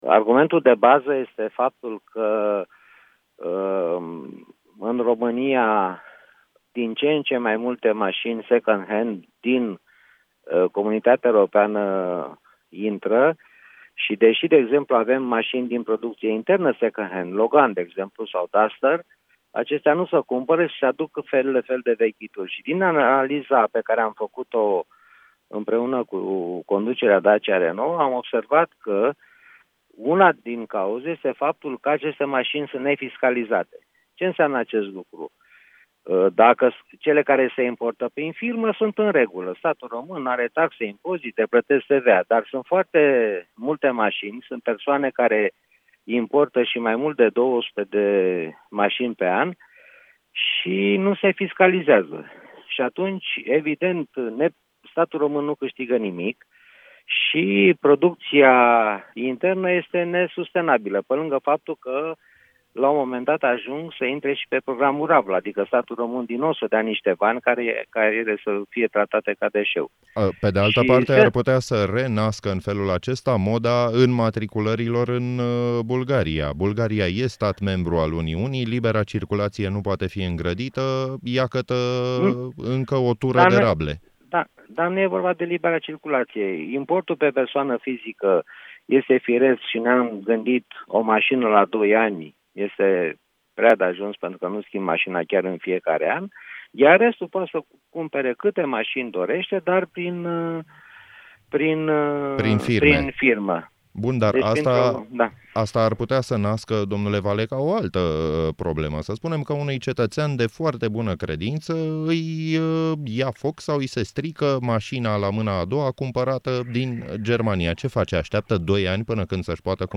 Într-un interviu acordat la Europa FM, în emisiunea Drum cu Prioritate, senatorul Șerban Valeca a vorbit despre acest proiect de lege:
03-nov-DCP-intv-serban-valeca.mp3